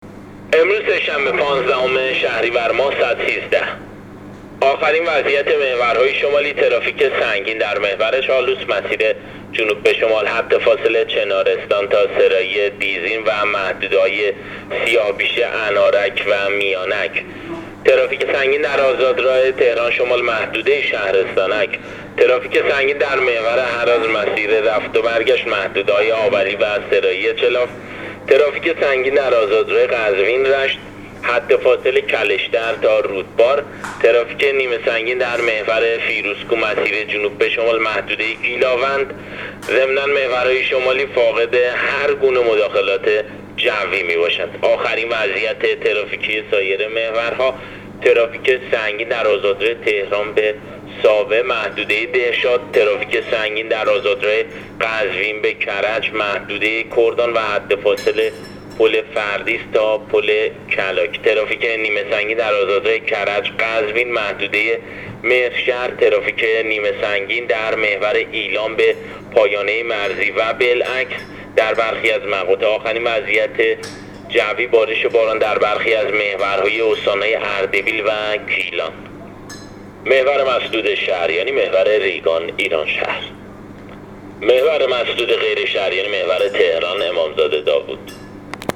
گزارش رادیو اینترنتی از آخرین وضعیت ترافیکی جاده‌ها تا ساعت ۱۳ پانزدهم شهریور؛